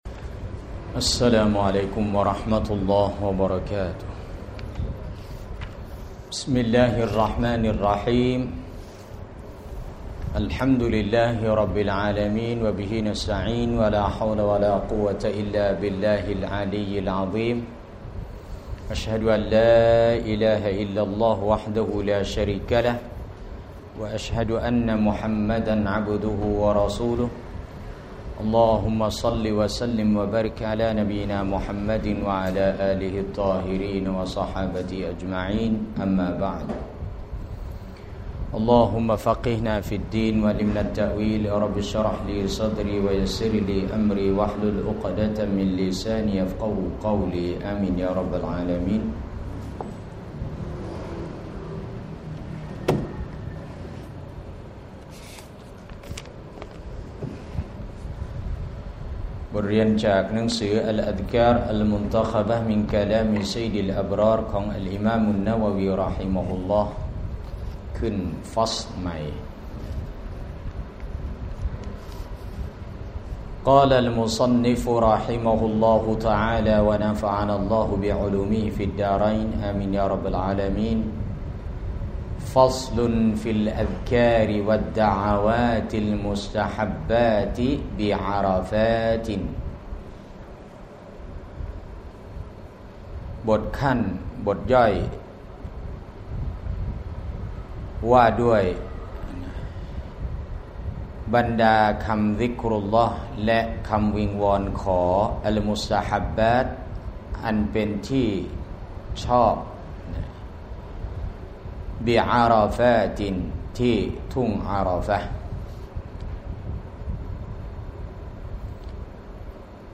สถานที่ : โรงเรียนญะมาลุ้ลอัซฮัร, อิสลามรักสงบ ซ.พัฒนาการ 30